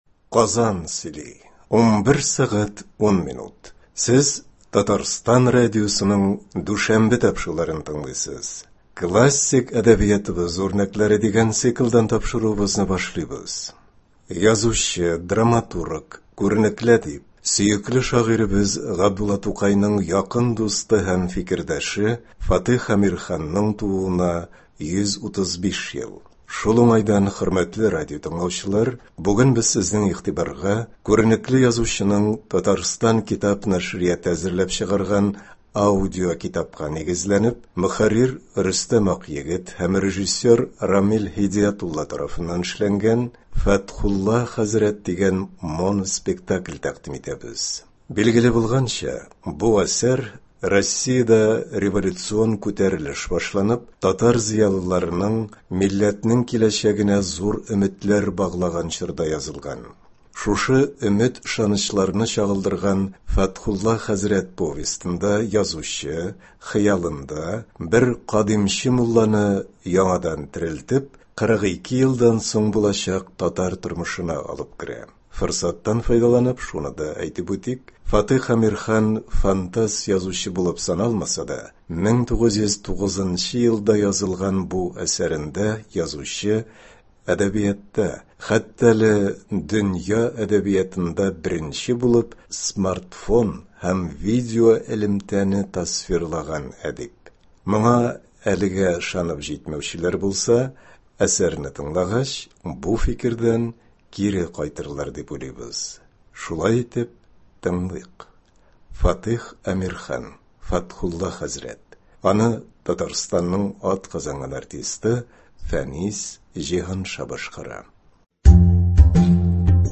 Фатыйх Әмирхан. “Фәтхулла хәзрәт”. Моноспектакль. 1 өлеш.